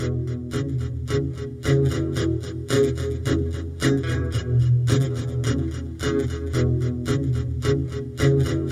皮奇弗克原声吉他介绍
描述：Em调的原声唱法（也可用于大调和弦）
Tag: 110 bpm Chill Out Loops Guitar Acoustic Loops 1.47 MB wav Key : E